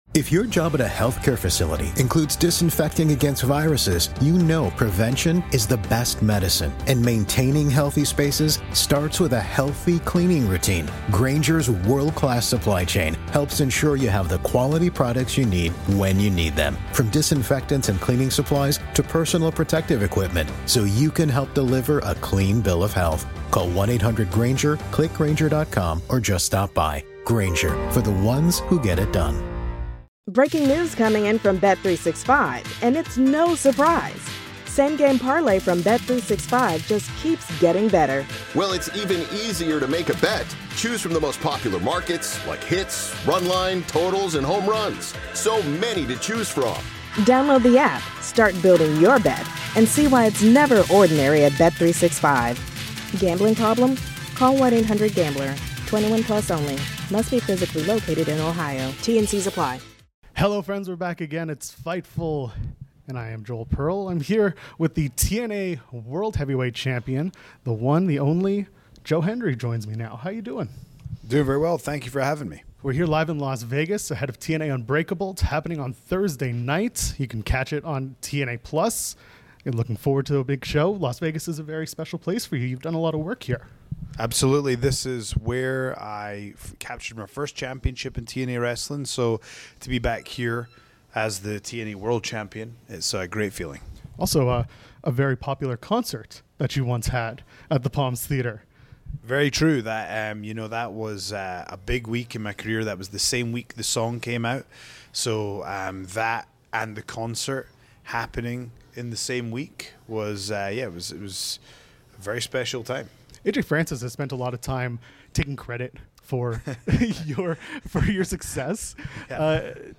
Shoot Interviews Apr 16